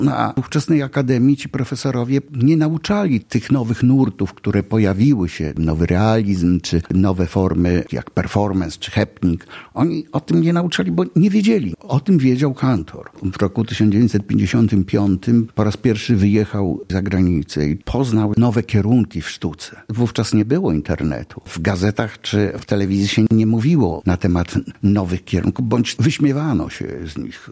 podkreślił w rozmowie z nami